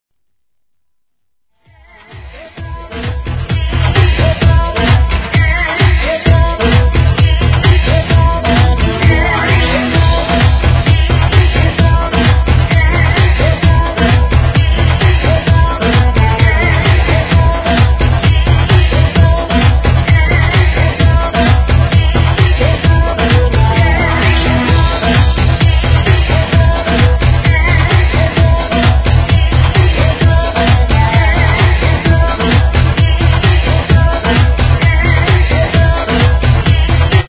HIP HOP/R&B
(130BPM)